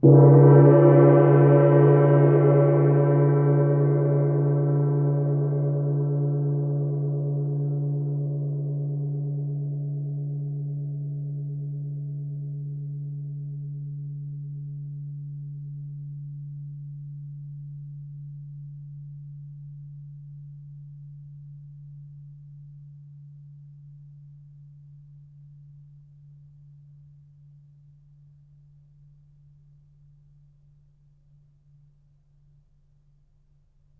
gongHit_mf.wav